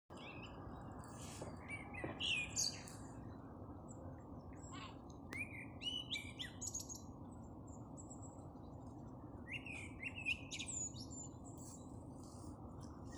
Blackbird, Turdus merula
Administratīvā teritorijaRīga
StatusSinging male in breeding season